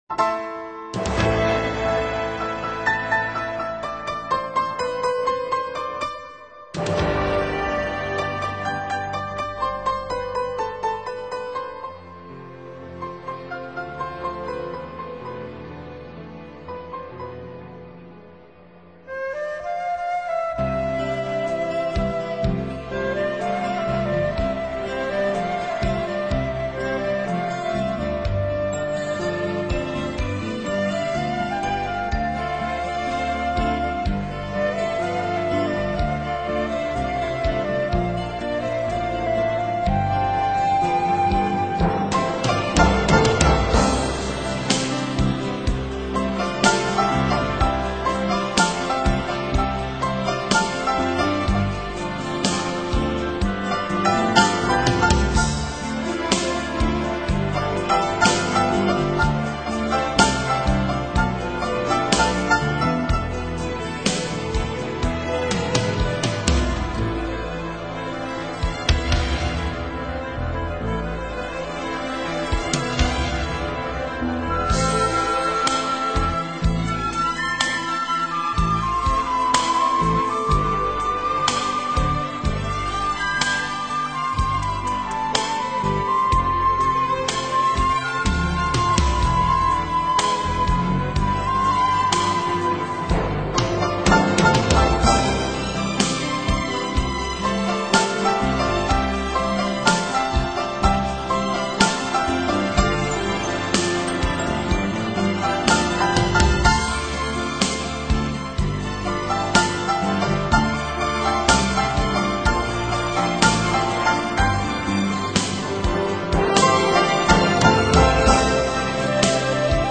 本专辑共耗费了120个小时录音时数，并搭配了75人编制的大型管弦乐团，合奏11首全新曲目，制作用心无以言喻。